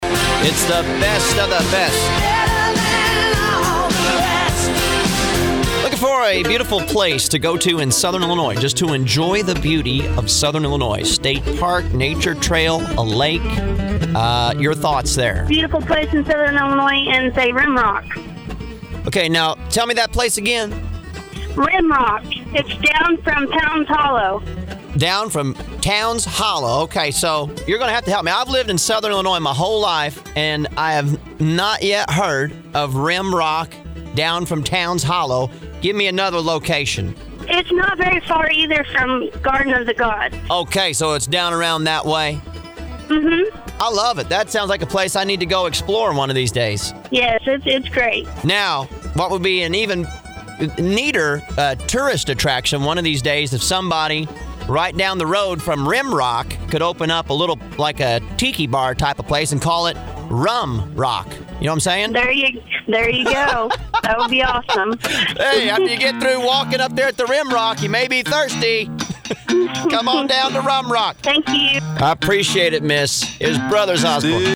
Rim-Rock-Call.mp3